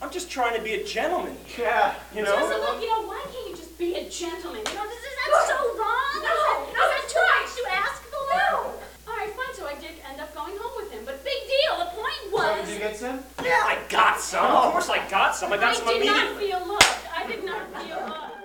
Transactors doing improv... sounds like fun!